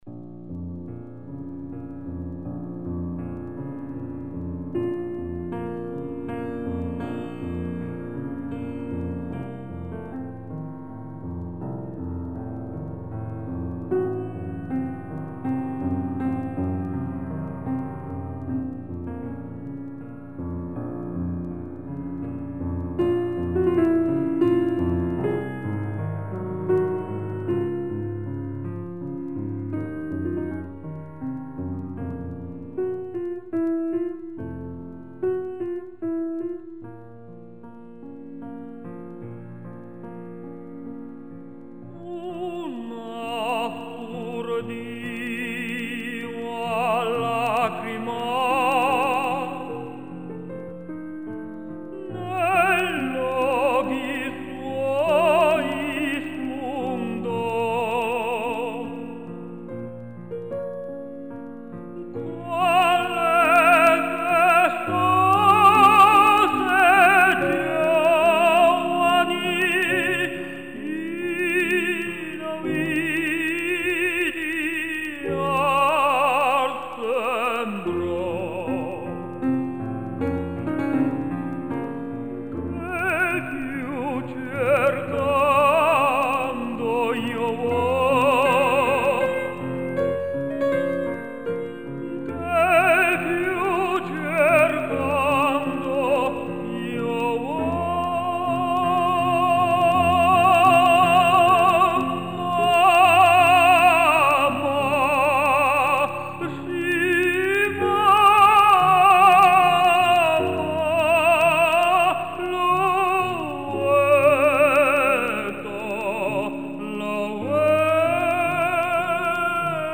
The orchestra part has been transcribed for piano.
• Nemorino, a young peasant (tenor)